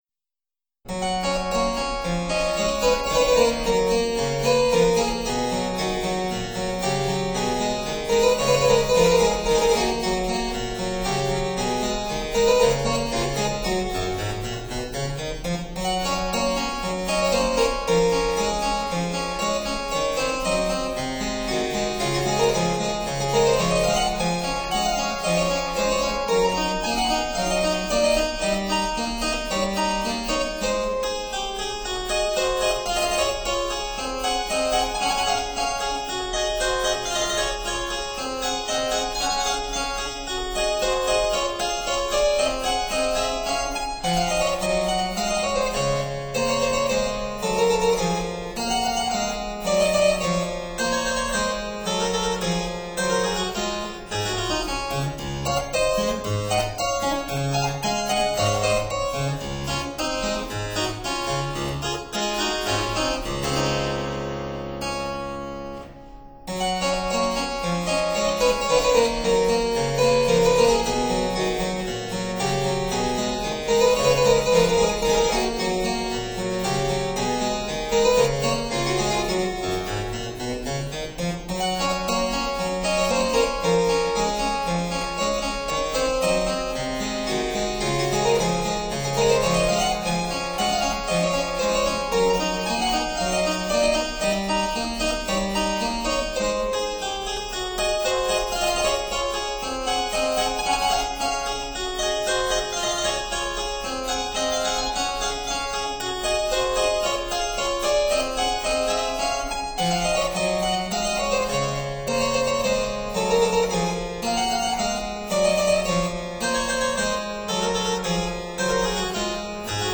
Six Harpsichord Sonatas Op. 1 (ca. 1780)
Pastorale in C major for Organ
Harpsichord & Organ
(Period Instruments)